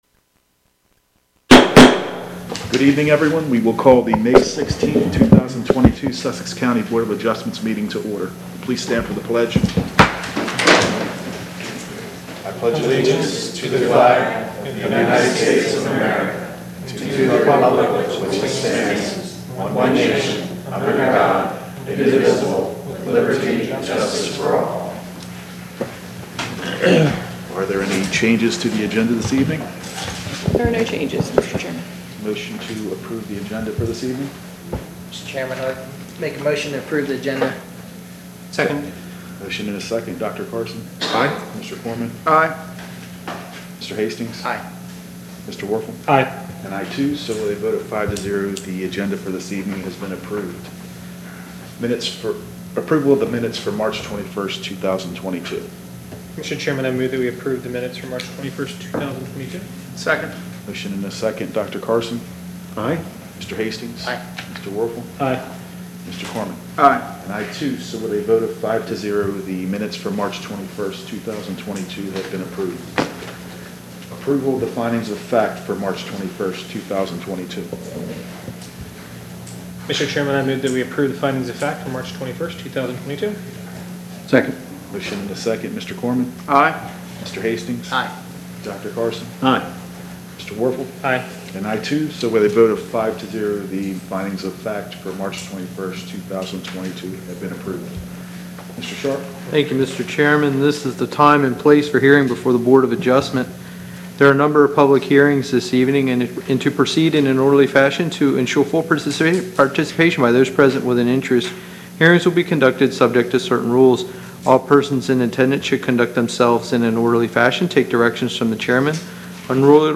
Board of Adjustment Meeting
Council Chambers, Sussex County Administrative Office Building, 2 The Circle, Georgetown